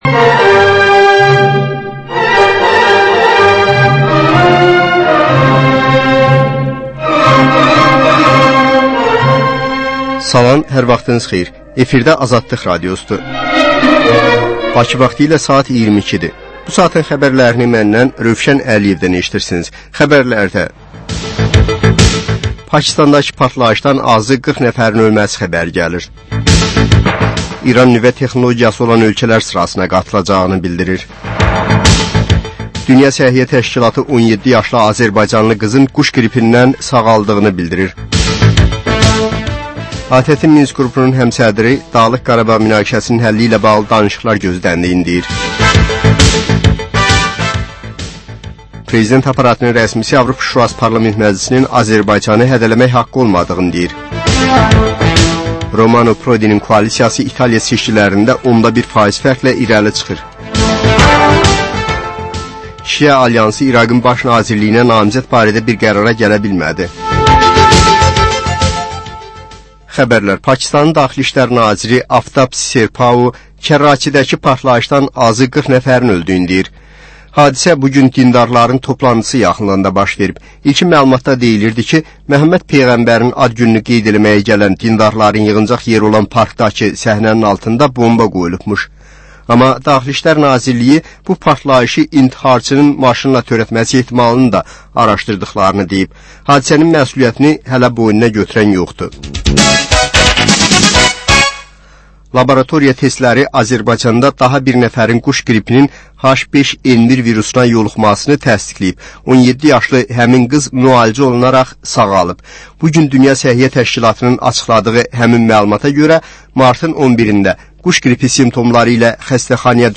Xəbərlər, reportajlar, müsahibələr